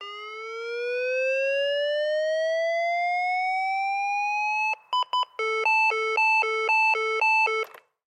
Звук запуска дефибриллятора